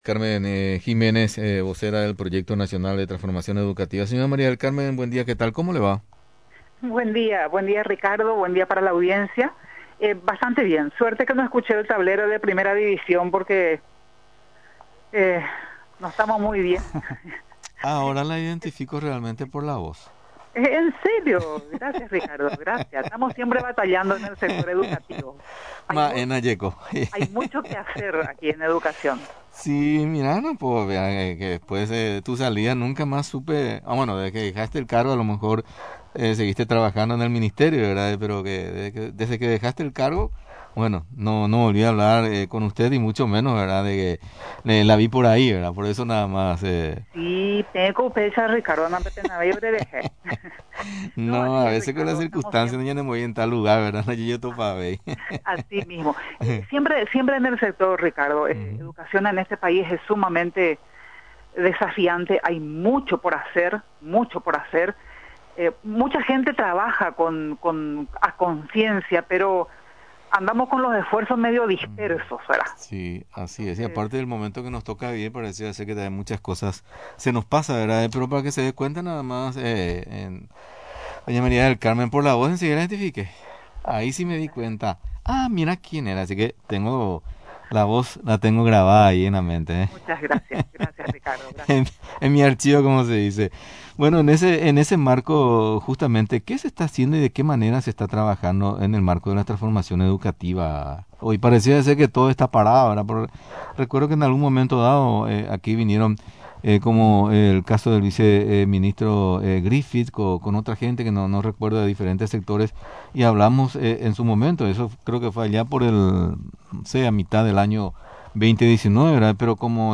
La viceministra de Educación María del Carmen Giménez sostuvo que la transformación educativa presenta varios aristas.